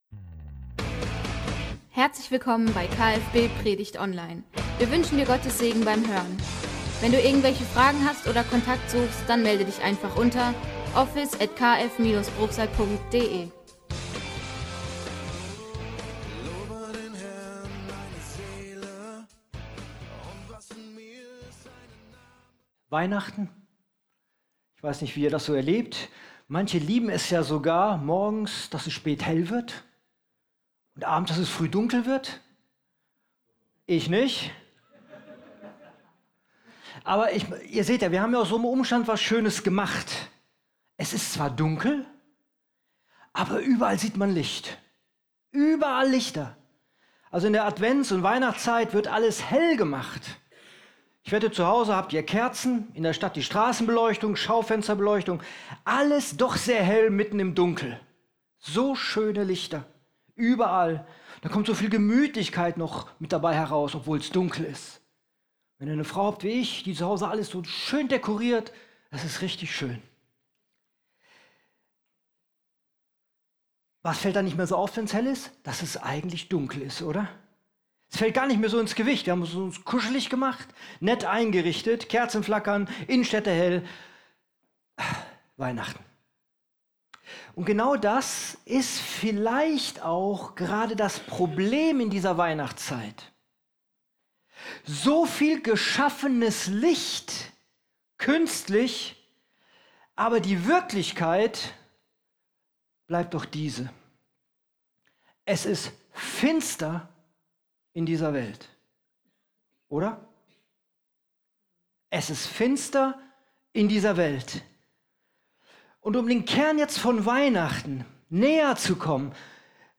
Licht der Welt ~ Predigtpodcast - Kirche für Bruchsal Podcast